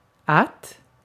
Ääntäminen
IPA : /ˈðæt/ US : IPA : [ðæt]